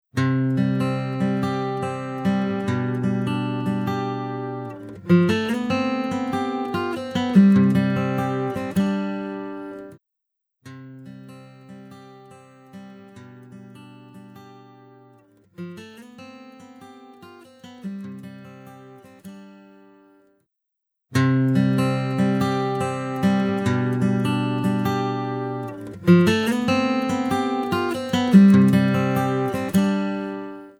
The clip contains 3 repetitions of a riff, as shown below. First, it’s the original riff. Then, with the effect applied, we hear the “phase cancelled” difference between both tracks.
And finally, we hear the effected riff, with no cancellation.